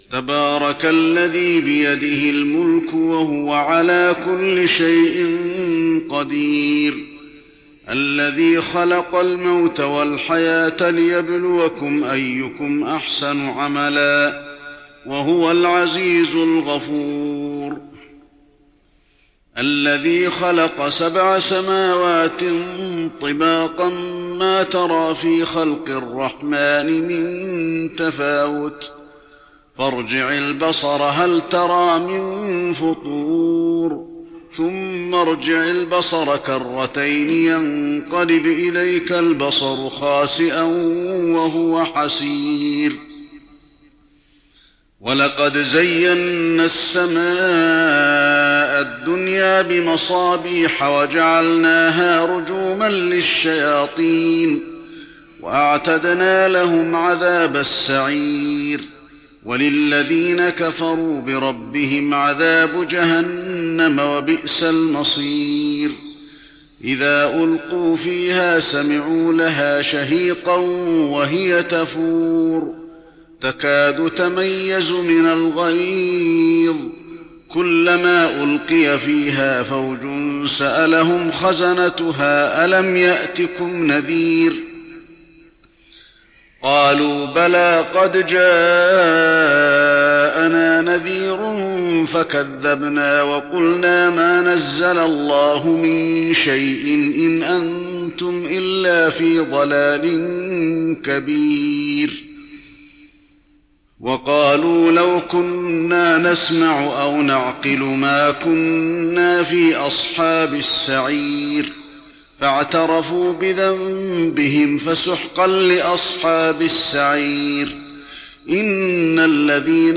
تراويح رمضان 1415هـ من سورة الملك الى سورة نوح Taraweeh Ramadan 1415H from Surah Al-Mulk to Surah Nooh > تراويح الحرم النبوي عام 1415 🕌 > التراويح - تلاوات الحرمين